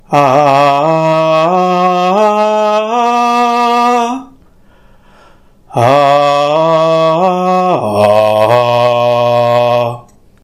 File File history File usage Metadata Mêlázêla_tones.ogg  (Ogg Vorbis sound file, length 10 s, 144 kbps) Summary Example of tones in Mêlázêla File history Click on a date/time to view the file as it appeared at that time.
Mêlázêla_tones.ogg.mp3